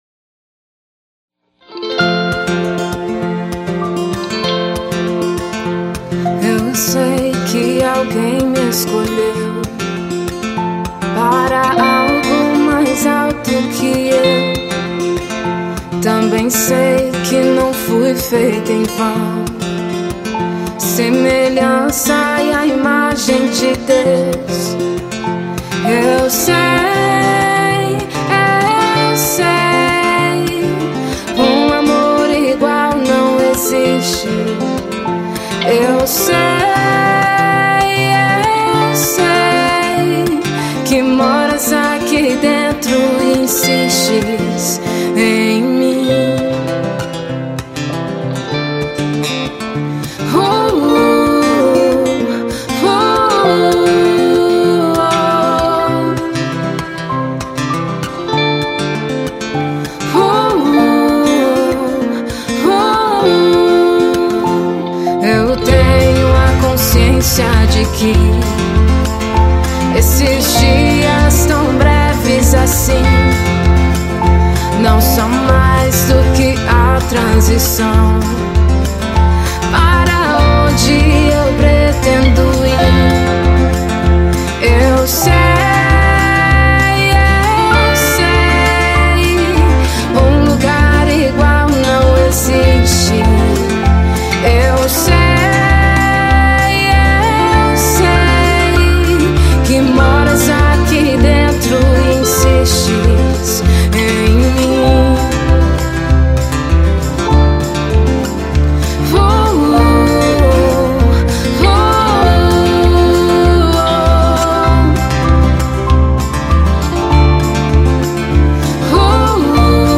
VOCALISTA
VIOLAO